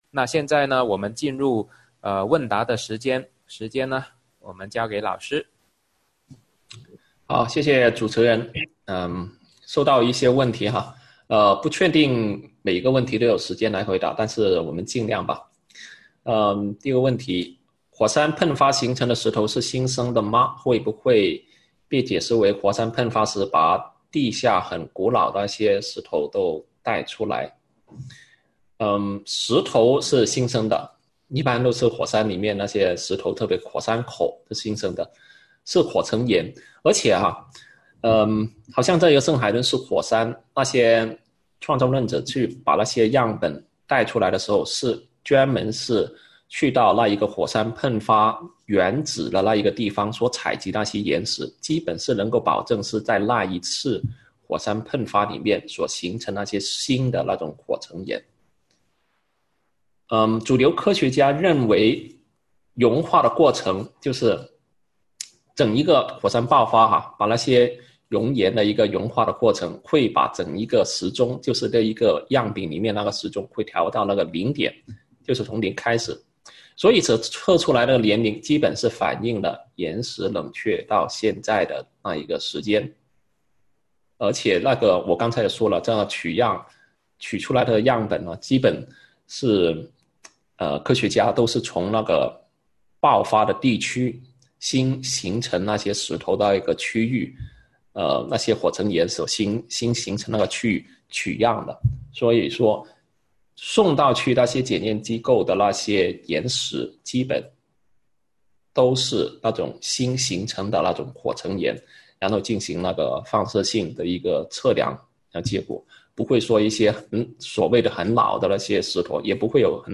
《带你识破地球“46亿岁”的虚假年龄》讲座直播回放